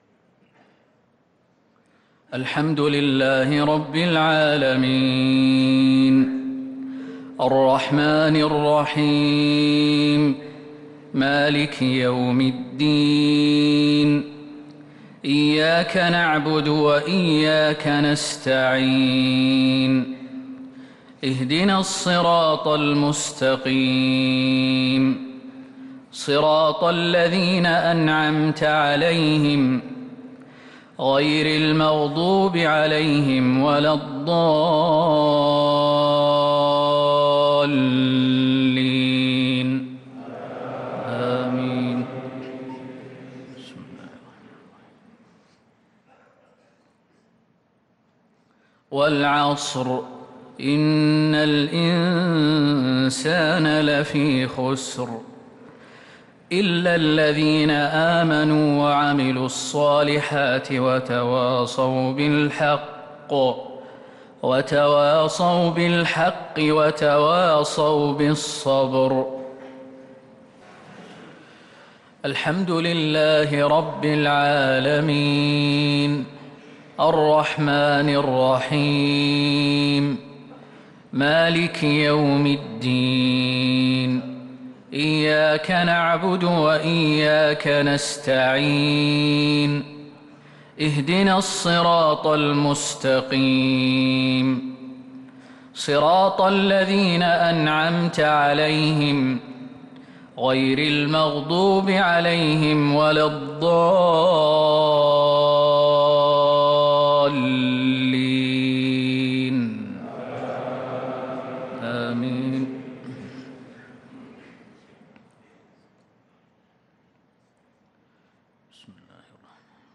صلاة المغرب للقارئ خالد المهنا 29 ذو الحجة 1443 هـ